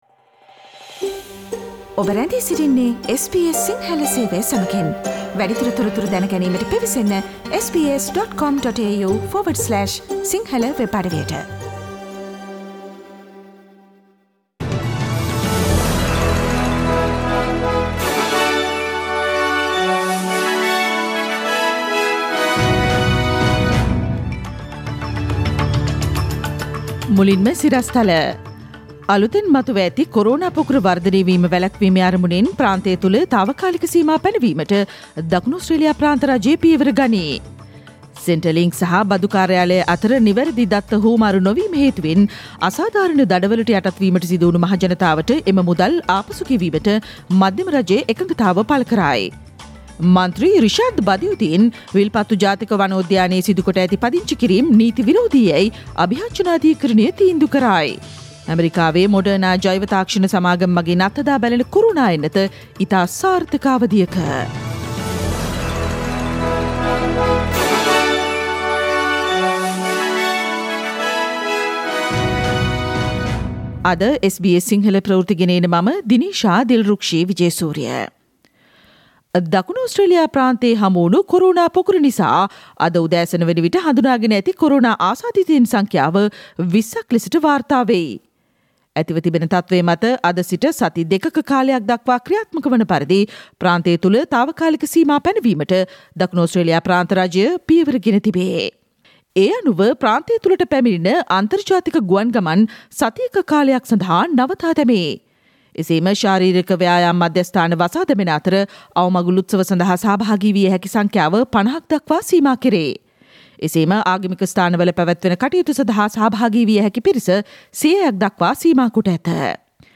Daily News bulletin of SBS Sinhala Service: Tuesday 17 November 2020
Today’s news bulletin of SBS Sinhala radio – Tuesday 1 September 2020.